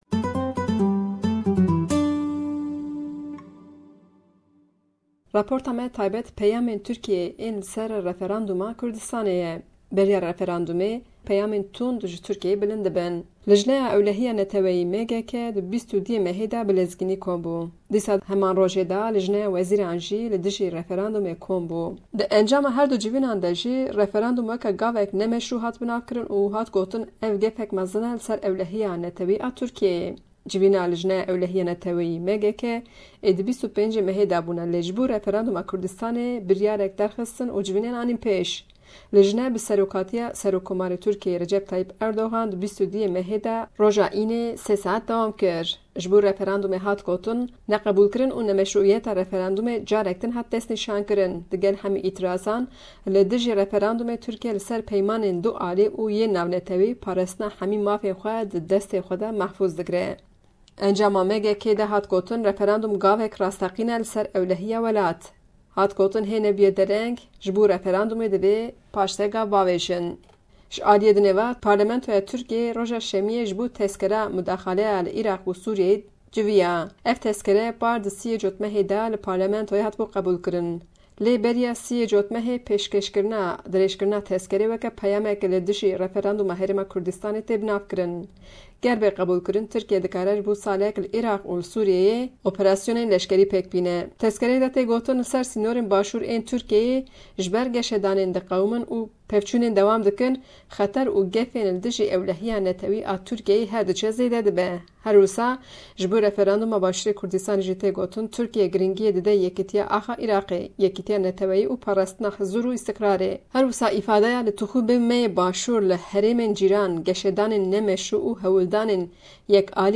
Raporta taybete